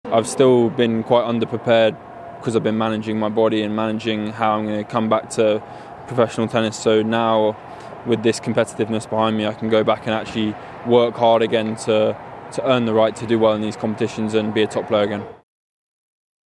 Jack draper speaks after exiting the Miami Open